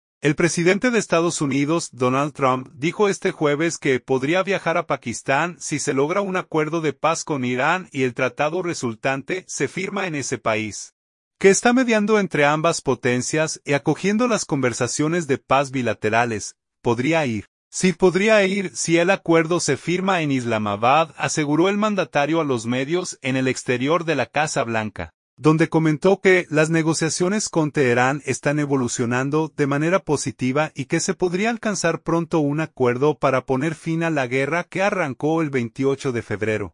«Podría ir, sí. Podría ir si el acuerdo se firma en Islamabad», aseguró el mandatario a los medios en el exterior de la Casa Blanca, donde comentó que las negociaciones con Teherán están evolucionando de manera positiva y que se podría alcanzar pronto un acuerdo para poner fin a la guerra que arrancó el 28 de febrero.